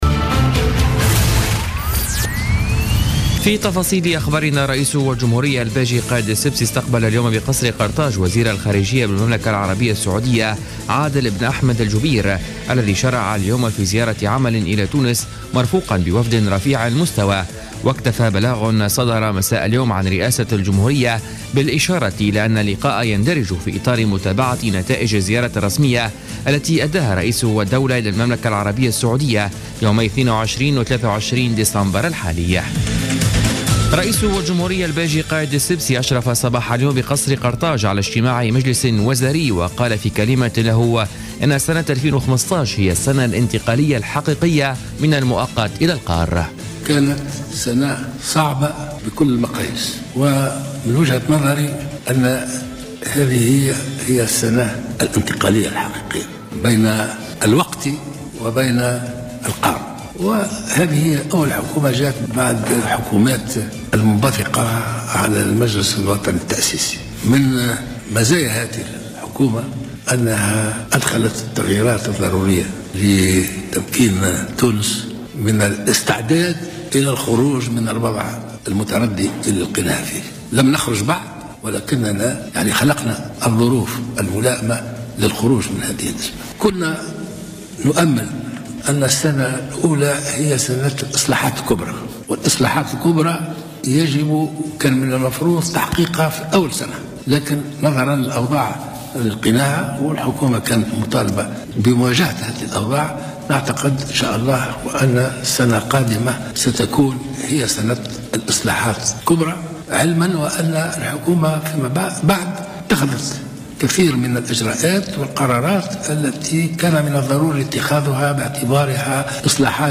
نشرة أخبار السابعة مساء ليوم الاربعاء 30 ديسمبر 2015